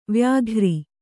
♪ vyāghri